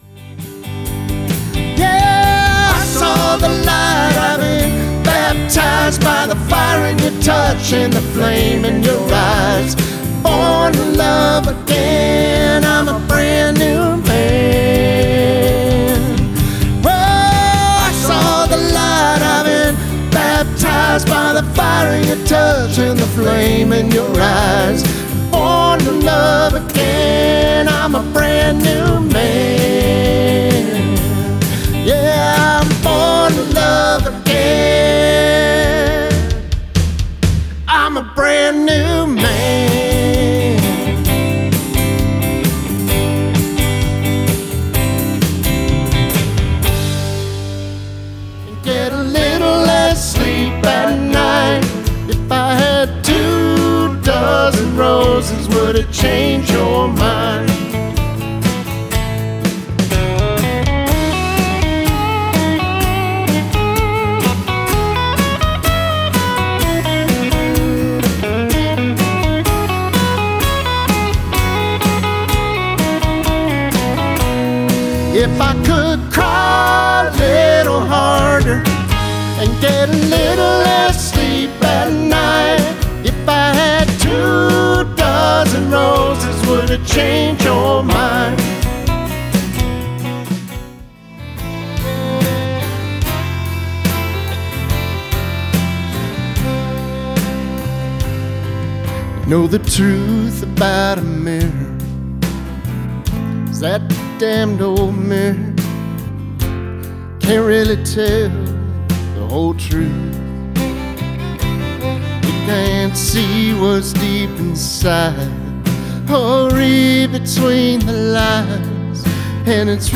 Live Audio Demo
Recorded Live with no Overdubs